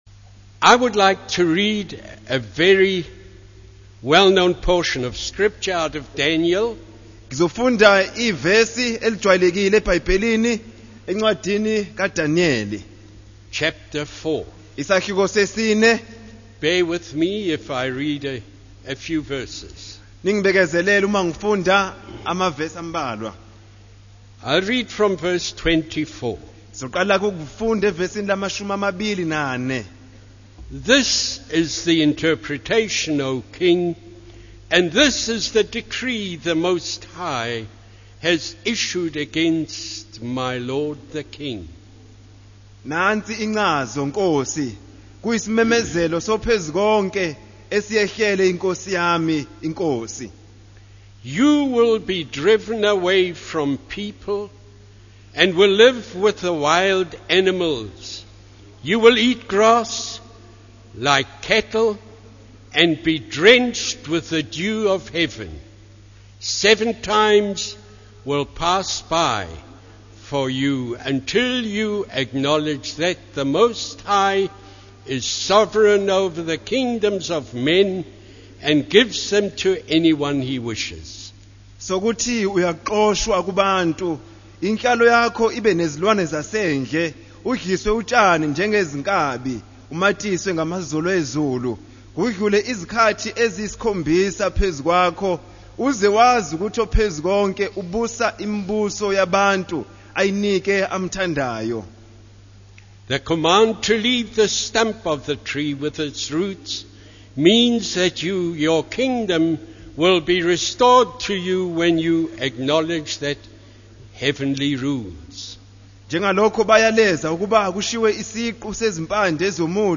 In this sermon, the preacher emphasizes the consequences of losing one's royal authority and the feeling of emptiness and desolation that comes with it.